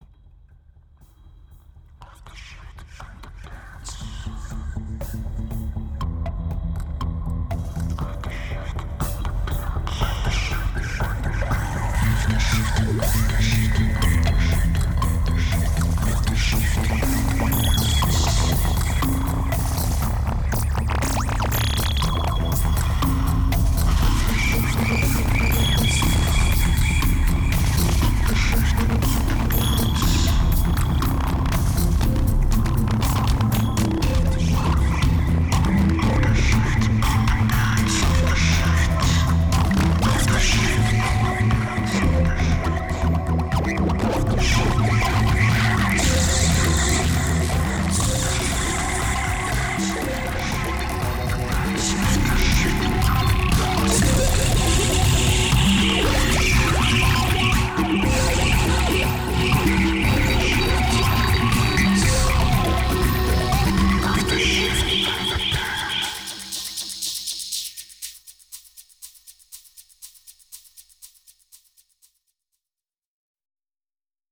2280📈 - -36%🤔 - 120BPM🔊 - 2009-04-23📅 - -938🌟
Introduction Echo Mistake Dark Nightmare Synthetic